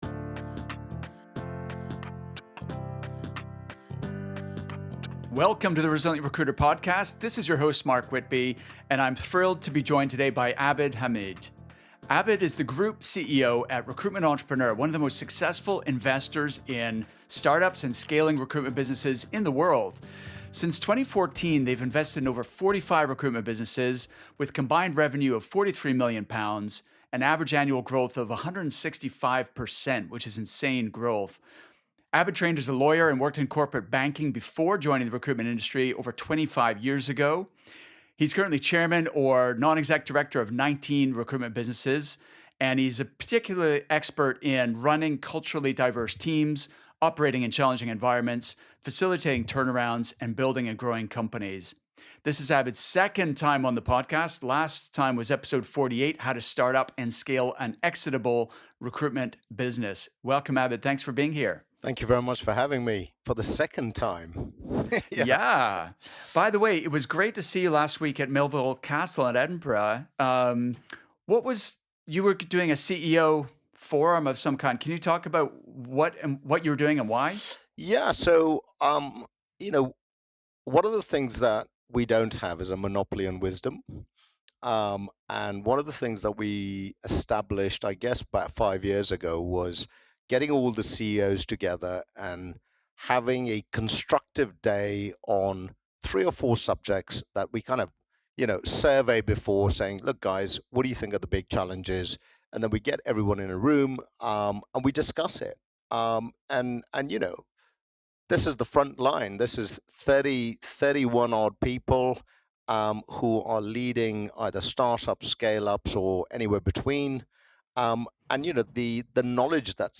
The information that he shares in this interview can make a valuable contribution to any size recruitment business.